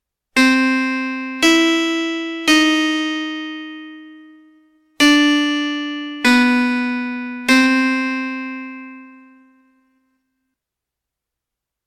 場面転換・オープニング・エンディング